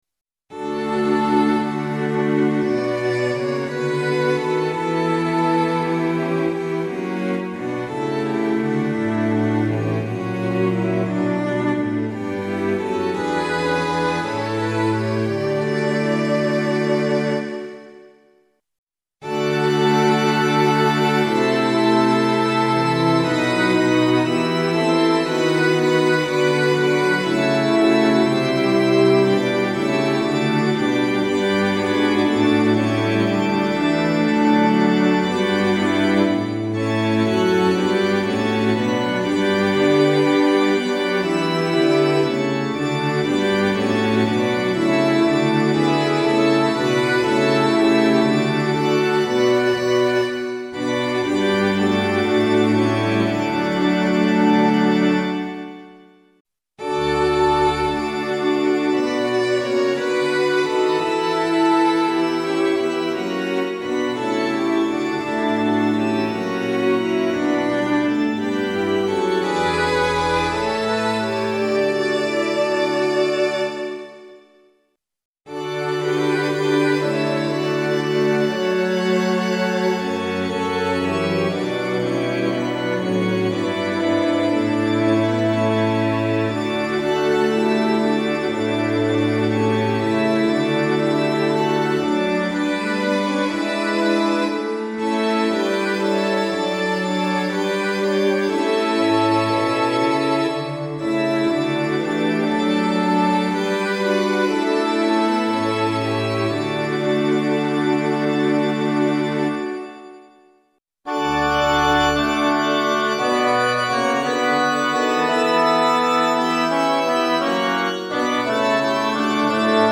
混声四部合唱+器楽 Four-part mixed chorus with Instruments
0.9.8.2 D Choir(S,A,T,B)